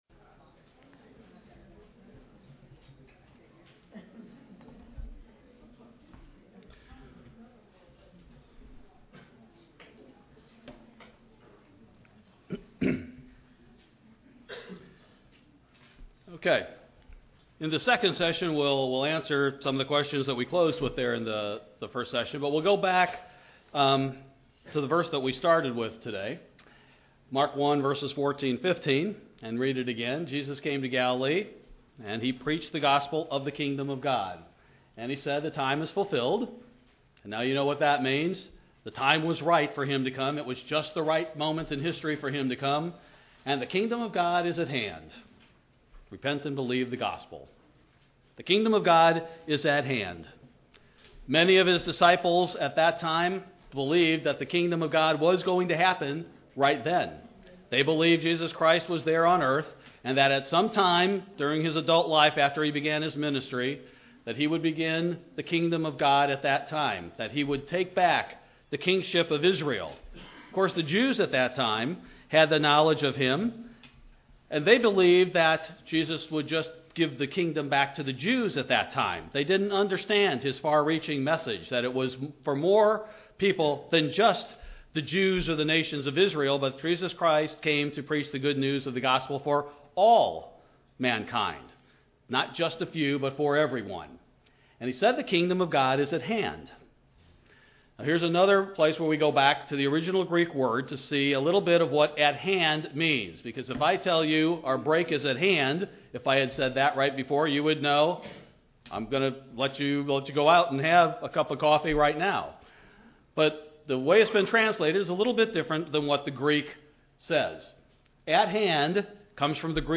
Second session of the Kingdom of God seminar. A look at Christs words in Mark 1:14.